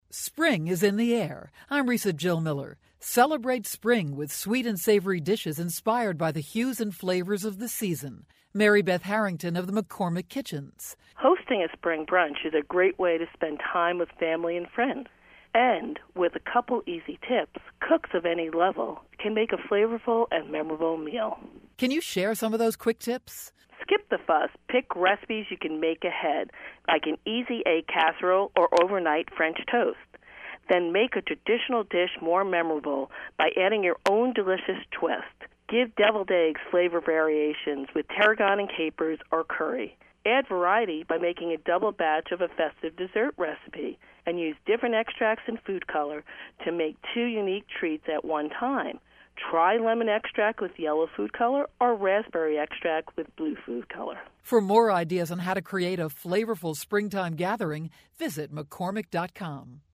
March 12, 2013Posted in: Audio News Release